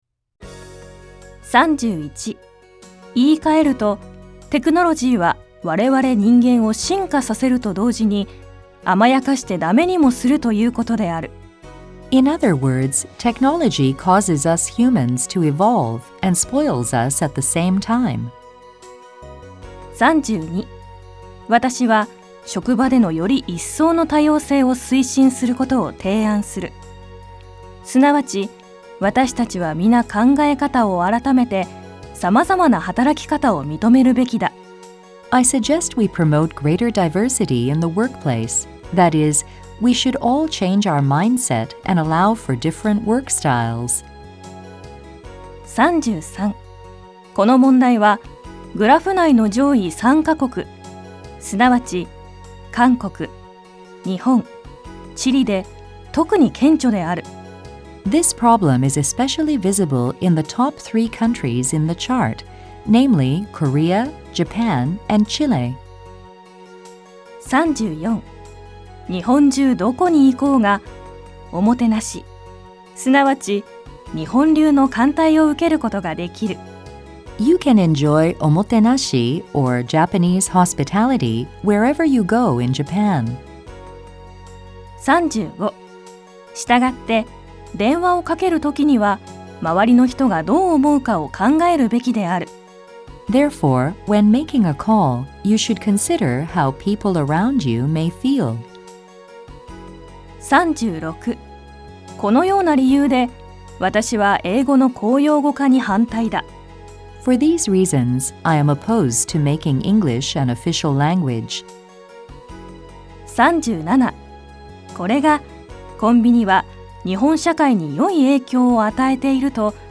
このページでは、『[自由英作文編]　英作文のトレーニング　改訂版』の別冊暗唱例文の日本語と英語の音声をダウンロードすることができます。